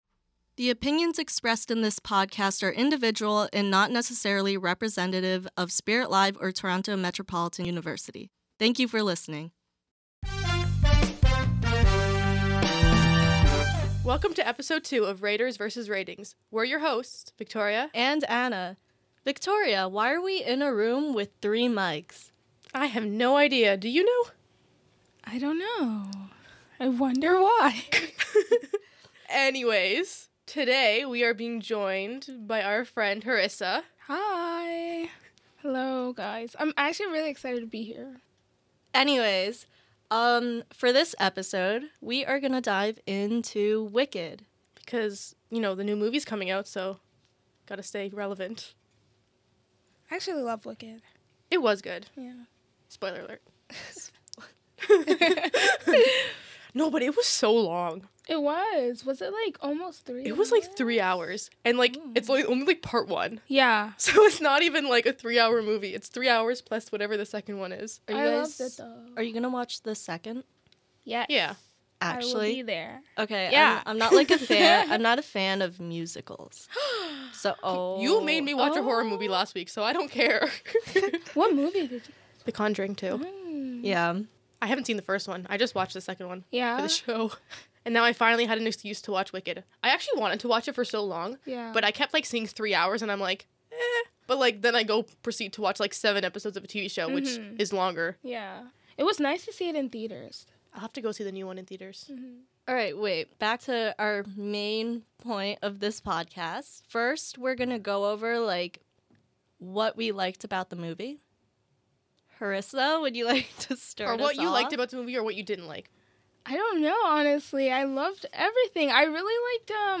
Each episode, we'll guess the official ratings of movies, then dive into lively discussions to see if they really deserve their scores. With a playful tone, fresh perspectives, and a mini ASMR break in the middle, Raters vs. Ratings is a fun and entertaining listen for anyone who enjoys film talk.